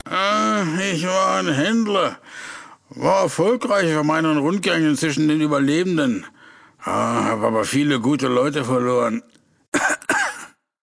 Kategorie:Fallout: Audiodialoge Du kannst diese Datei nicht überschreiben.